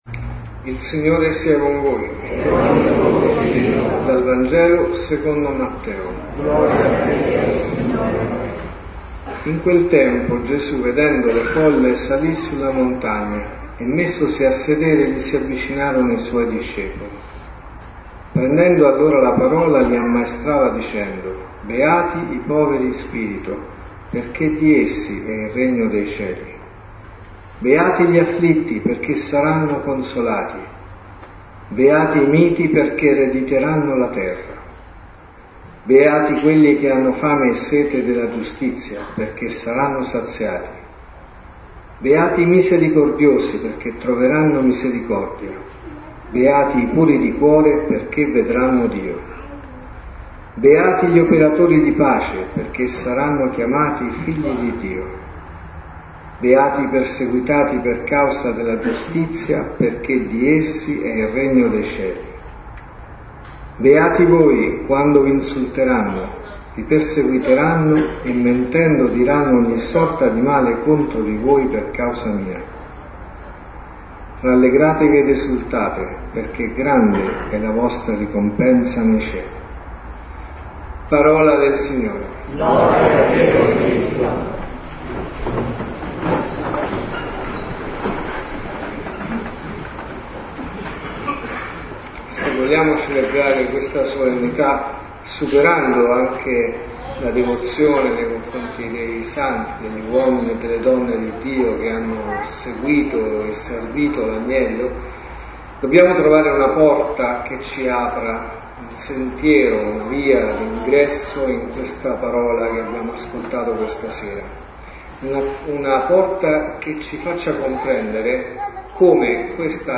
Omelie Messa della mattina